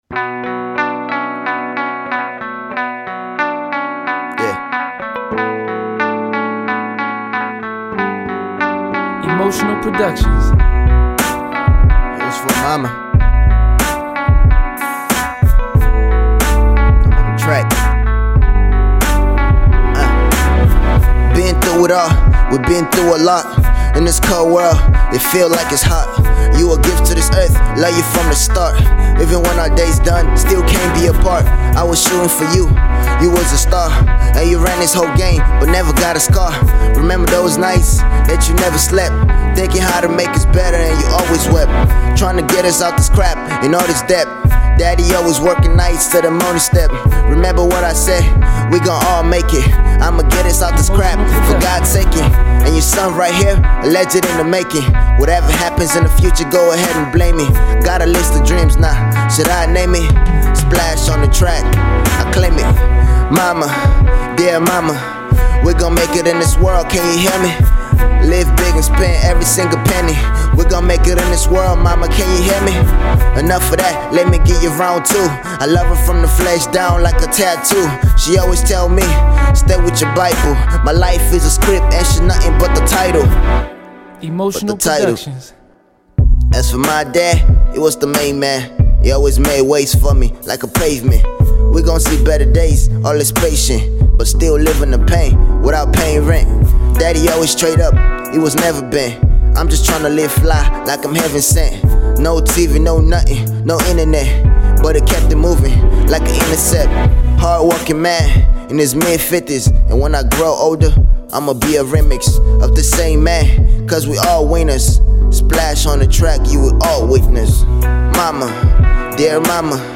young rapper